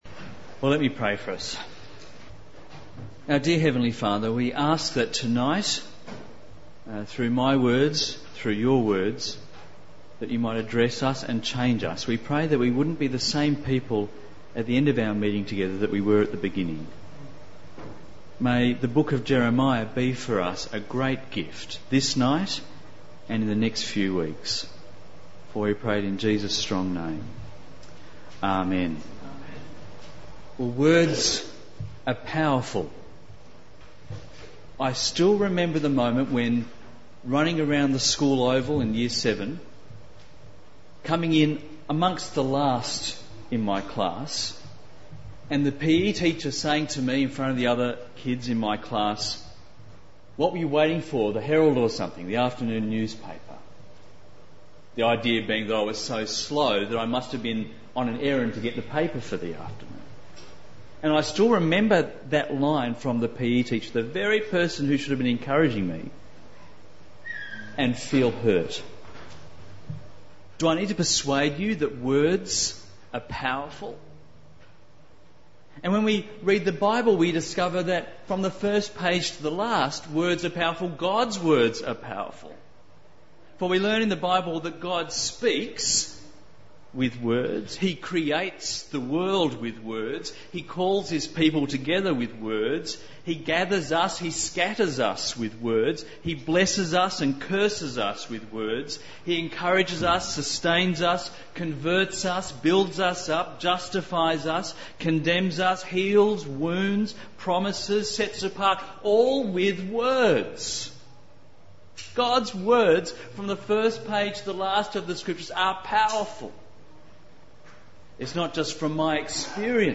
Sermons | St Alfred's Anglican Church
[post_id=1020] From this series Precious Words Jeremiah Jeremiah 36 Guest Speaker May 21, 2006 Current Sermon Powerful Words Jeremiah Jeremiah 1 Guest Speaker May 7, 2006 View all Sermons in Series